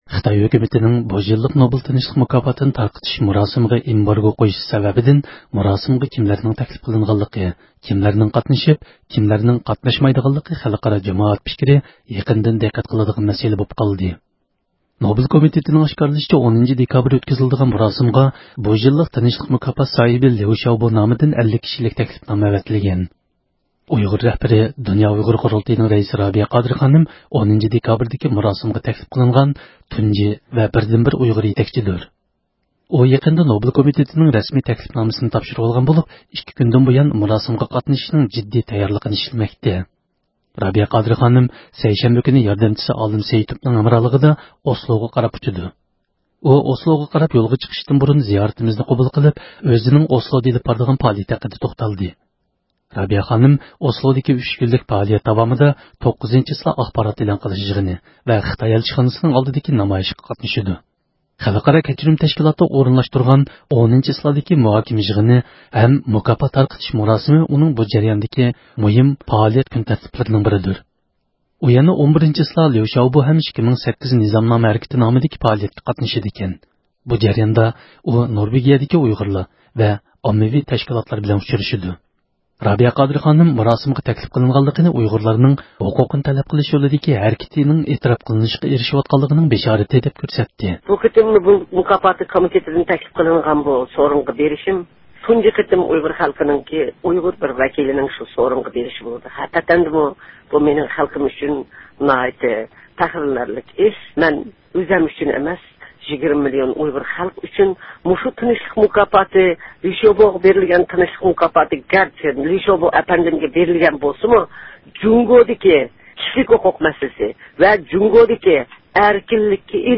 ئۇ، ئوسلوغا قاراپ يولغا چىقىشتىن بۇرۇن زىيارىتىمىزنى قوبۇل قىلىپ، ئۆزىنىڭ ئوسلودا ئېلىپ بارىدىغان پائالىيەتلىرىنى تونۇشتۇردى.